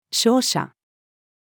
傷者-wounded-person-female.mp3